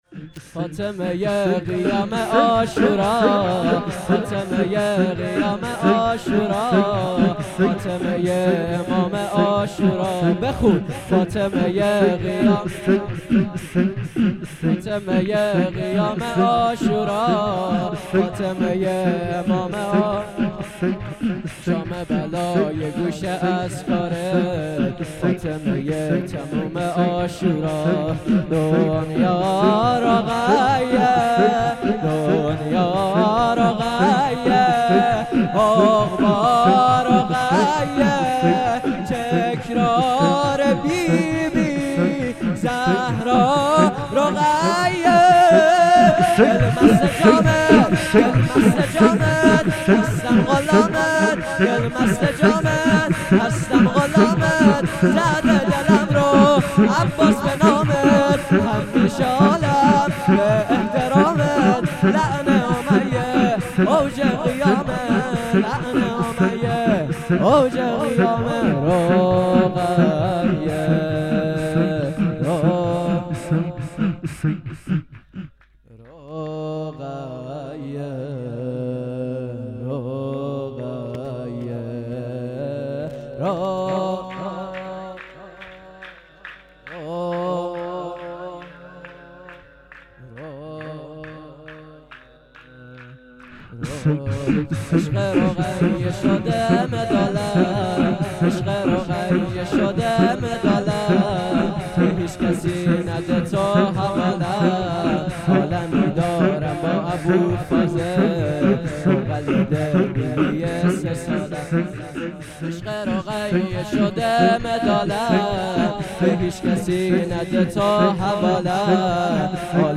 مسجد امام موسی بن جعفر علیه السلام | 4 اردیبهشت ماه 98
میلاد حضرت رقیه سلام الله علیها 98